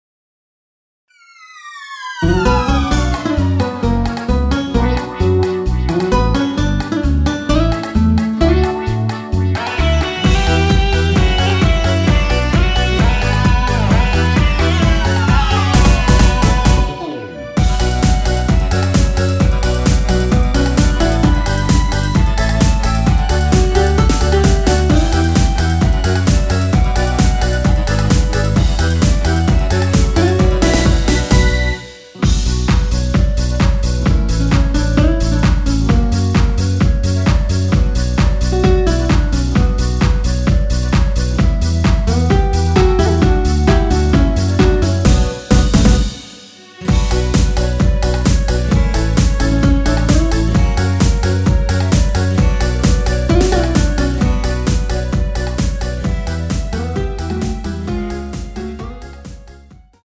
원키에서(-2)내린 멜로디 포함된 MR입니다.
Bm
앞부분30초, 뒷부분30초씩 편집해서 올려 드리고 있습니다.
(멜로디 MR)은 가이드 멜로디가 포함된 MR 입니다.